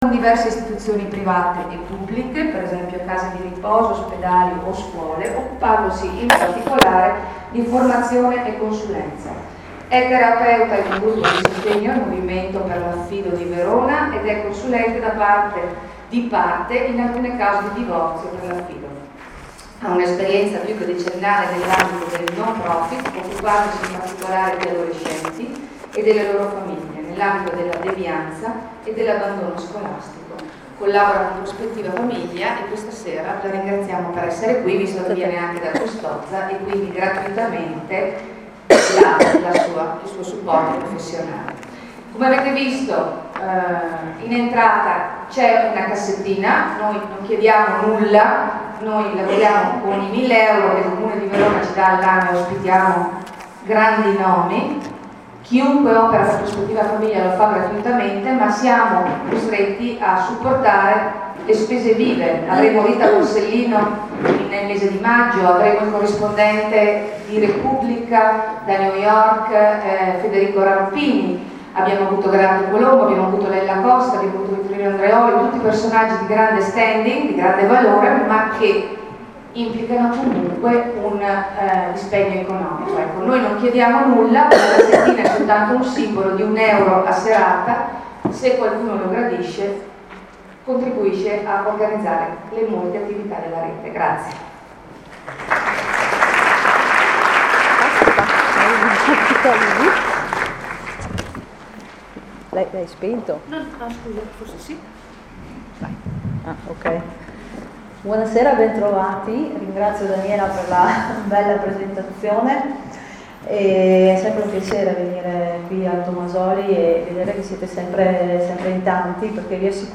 Scuola per Genitori ed Educatori di Prospettiva Famiglia